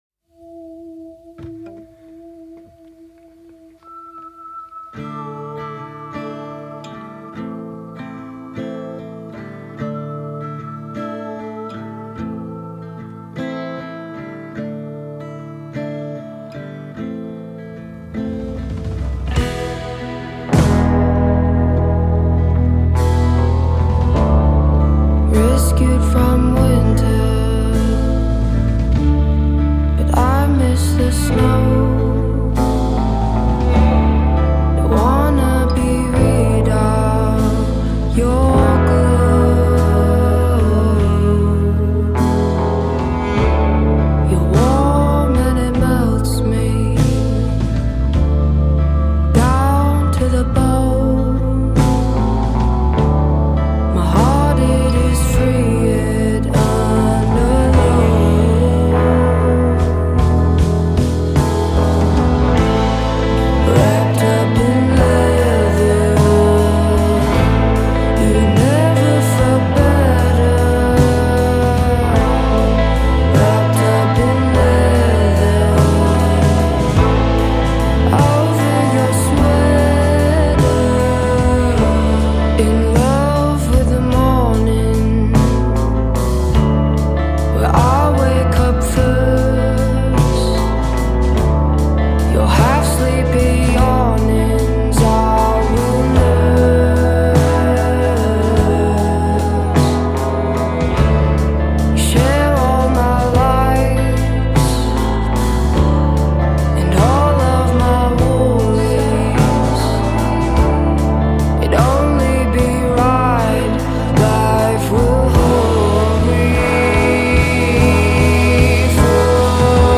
a few steps beyond ethereal.
characterized by honeyed vocals and haunting melodies.